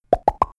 Click below to hear the end result of a cartoonish balloon-popping noise.